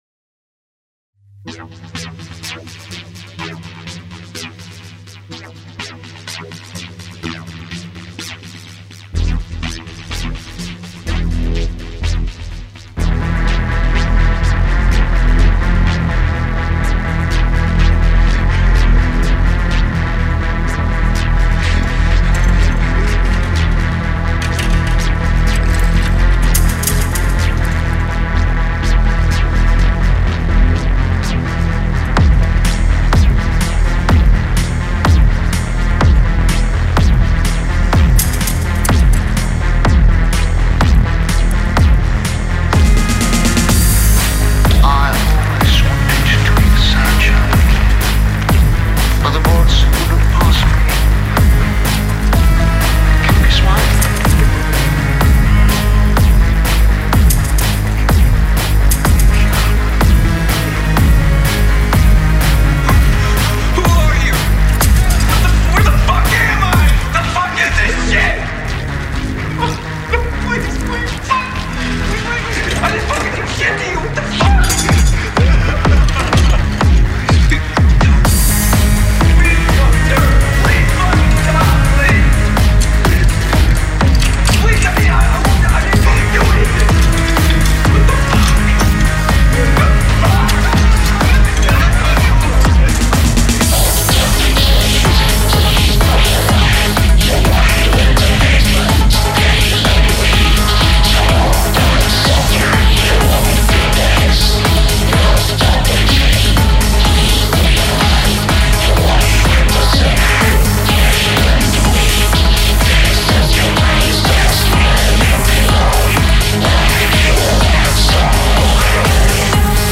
Dançante, inebriante e assustador.